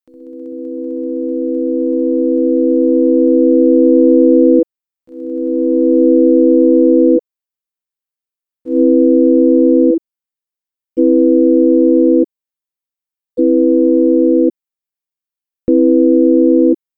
AR (Attack)
EXAMPLE: AR value at minimum (longest attack time), gradually raising to maximum: